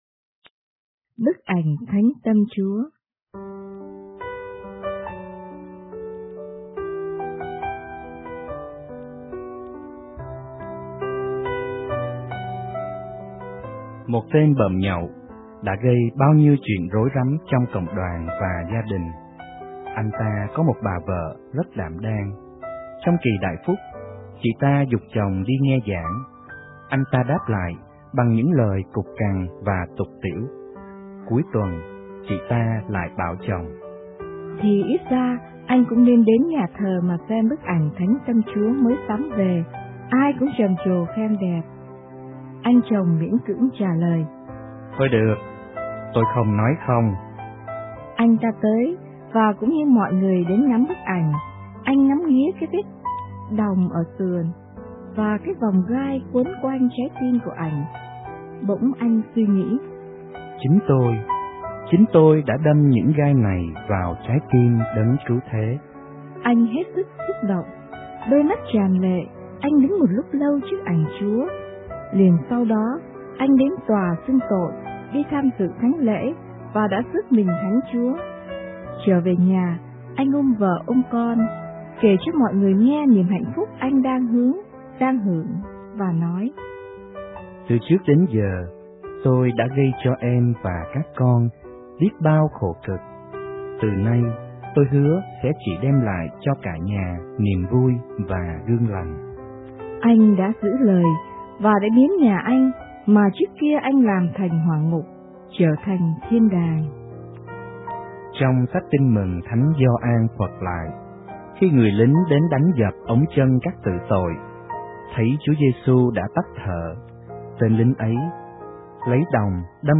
* Thể loại: Suy niệm